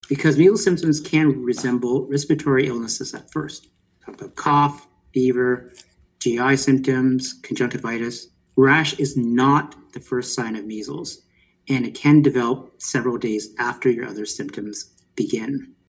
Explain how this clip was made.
Yesterday, Southwestern Public Health and Grand Erie Public Health hosted a media briefing to provide an update on the measles outbreak in the region.